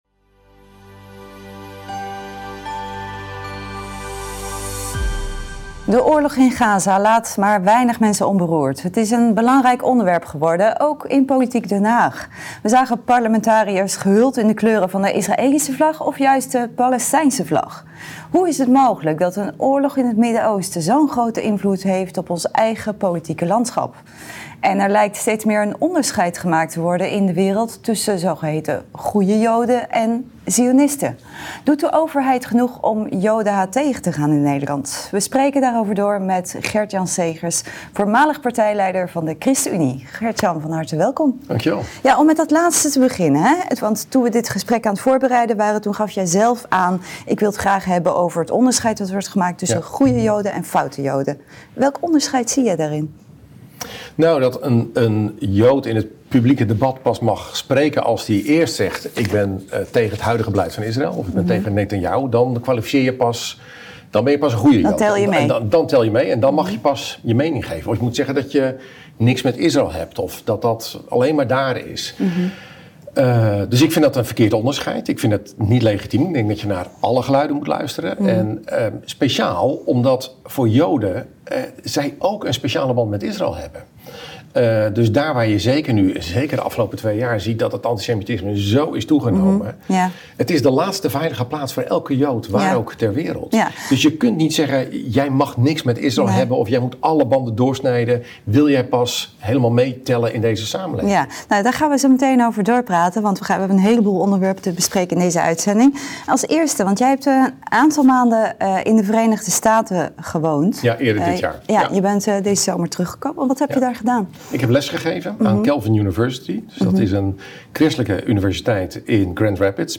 We spreken erover door met Gert-Jan Segers, voormalig partijleider van de ChristenUnie.